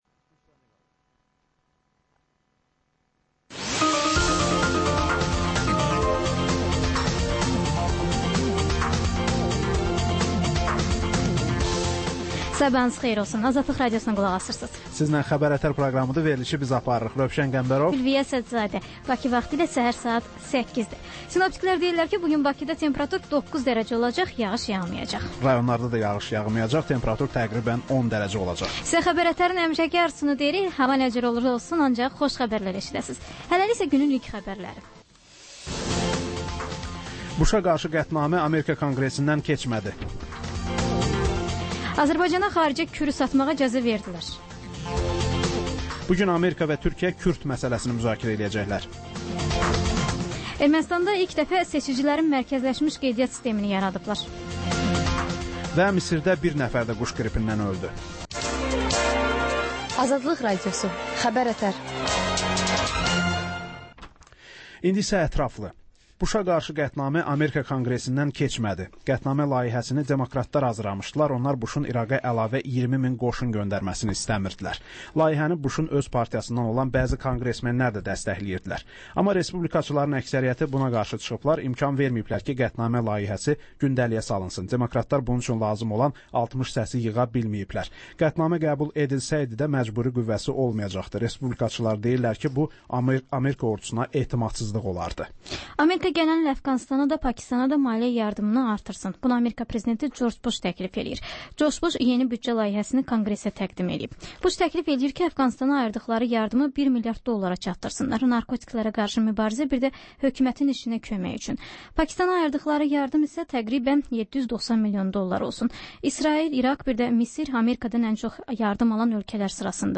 Səhər-səhər, Xəbər-ətər: xəbərlər, reportajlar, müsahibələr.